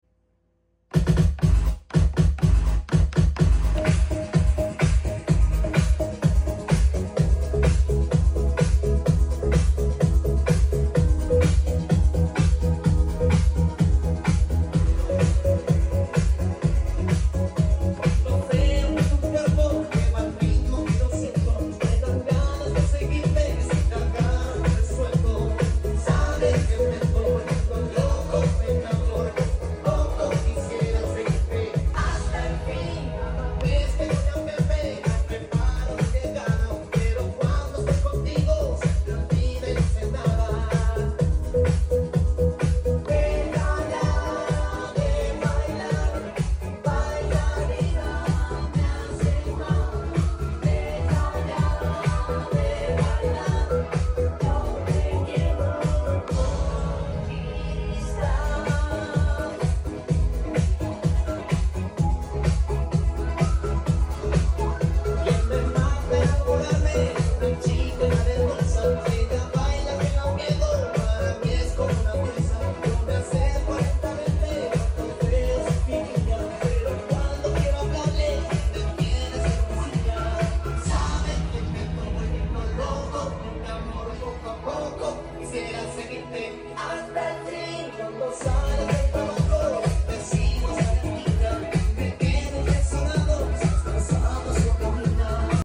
versión french house
#90smusic